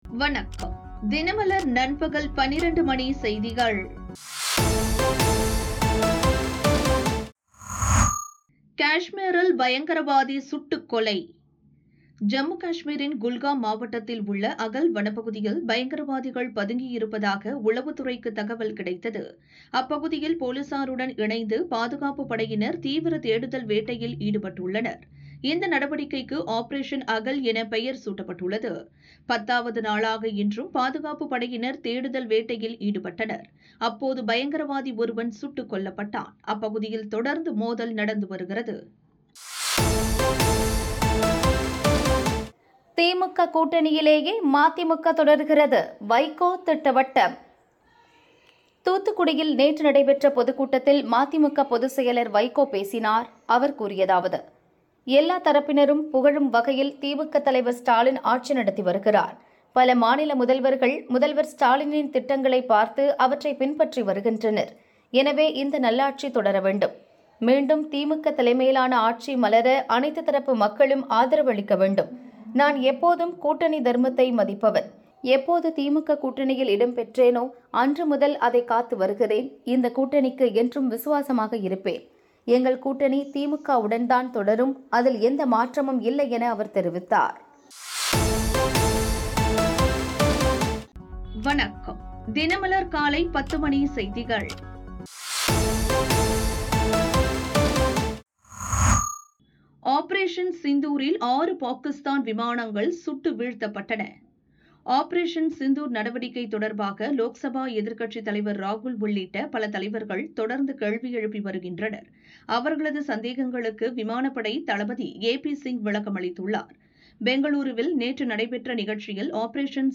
தினமலர் பகல் 12 மணி செய்திகள் - 10 AUG 2025